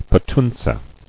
[pe·tun·tze]